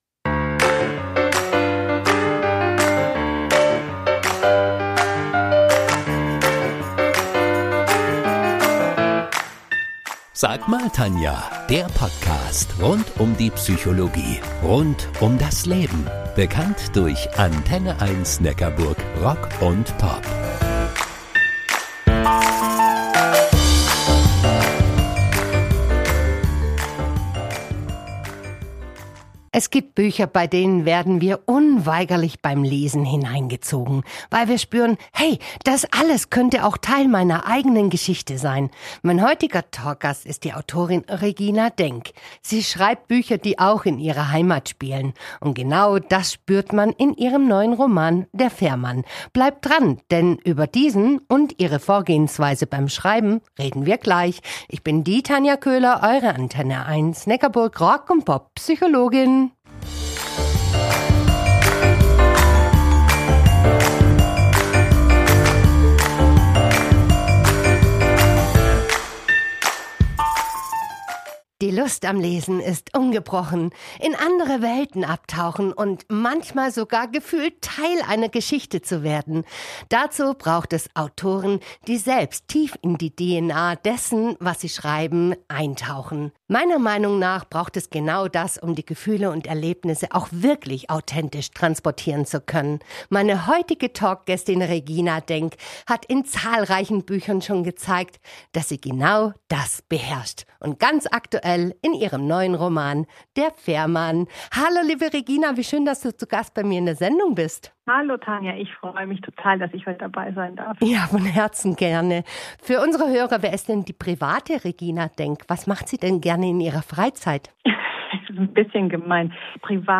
Im Gespräch
Diese Podcast-Episode ist ein Mitschnitt